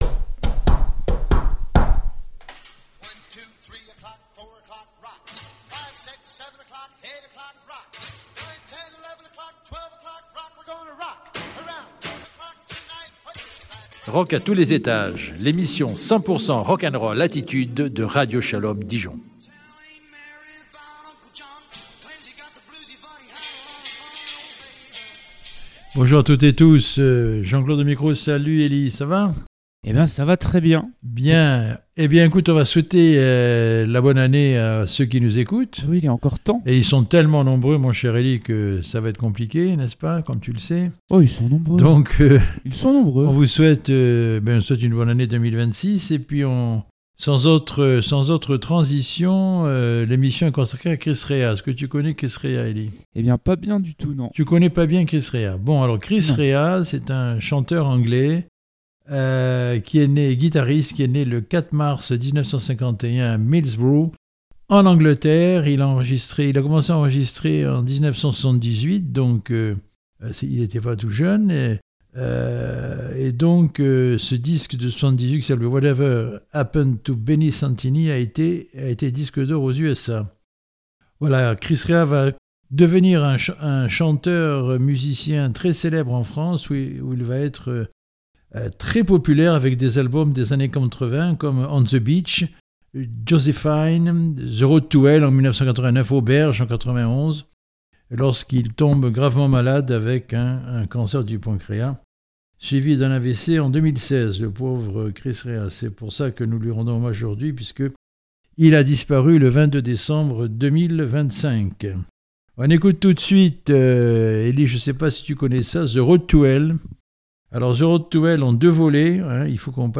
Bienvenue à toutes et à tous, dans votre émission de rock, qui vous replongent dans la carrière de rockeur trop méconnu.